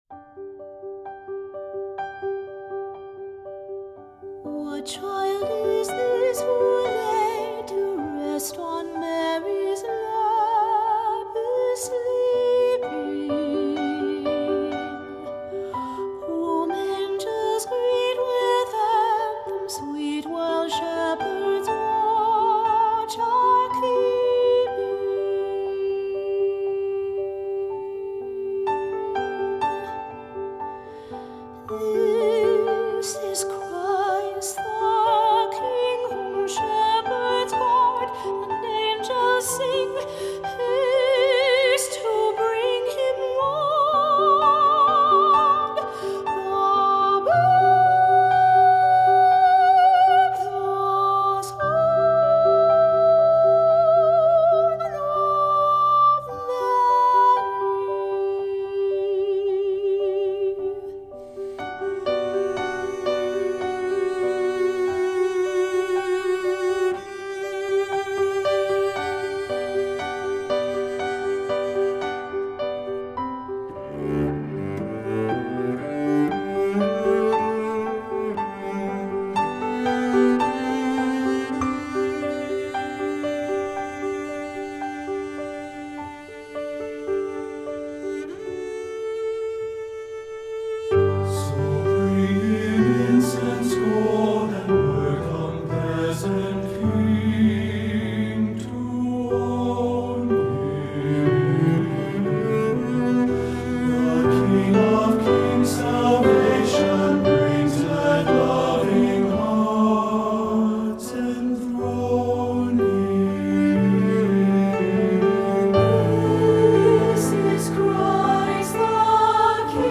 Voicing: SATB and Solo